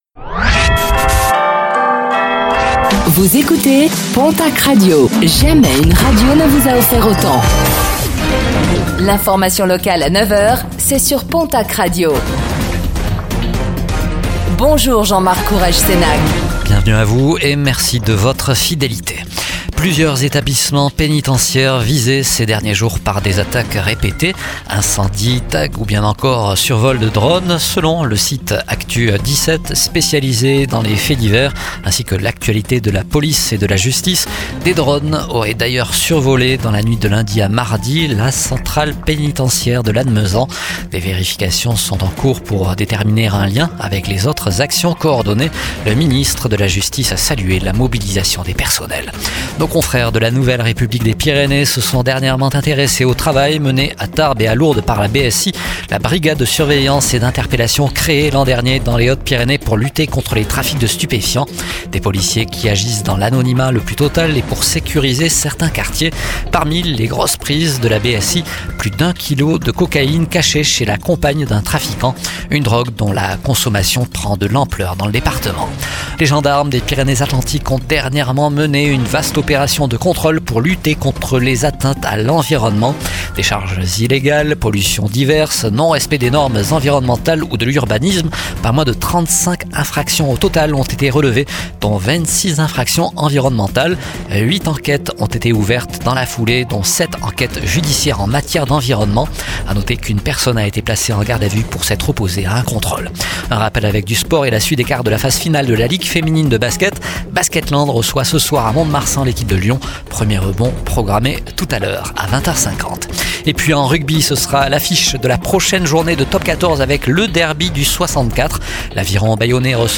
Réécoutez le flash d'information locale de ce mercredi 23 avril 2025